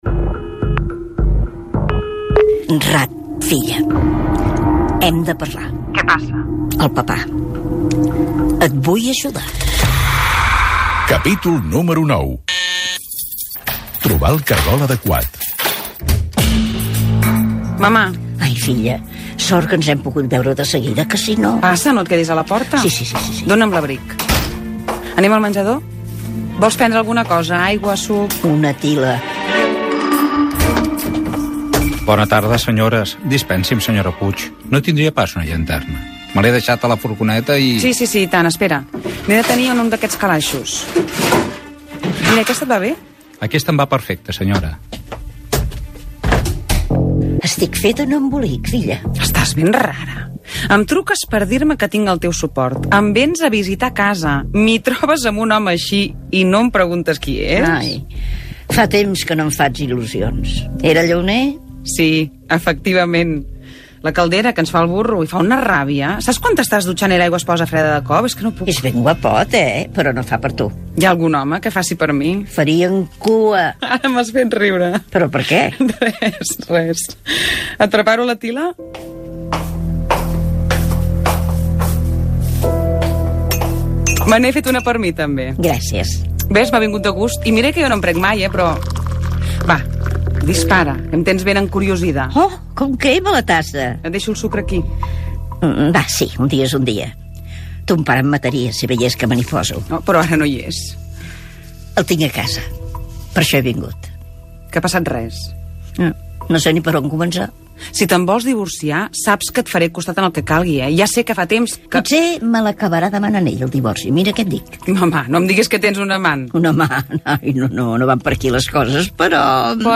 Radionovel·la - Amb mal peu - Catalunya Ràdio, 2017
CARULLA-Montse-Radionovella.-Amb-mal-peu-CatR-2017.mp3